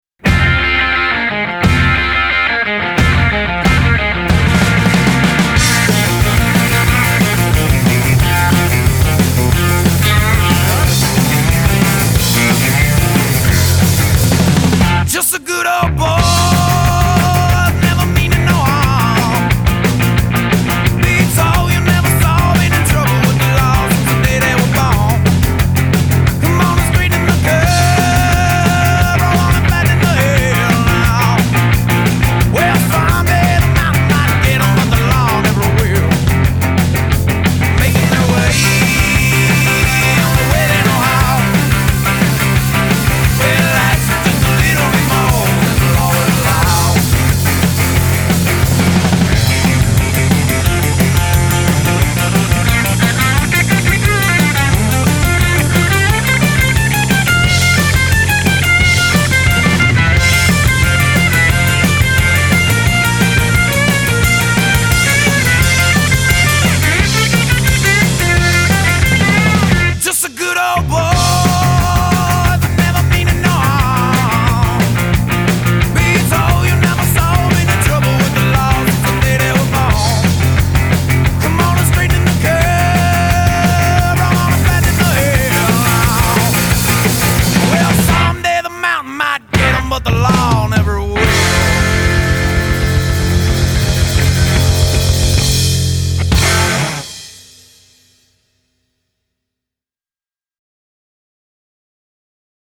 None Genre: Rock I Want More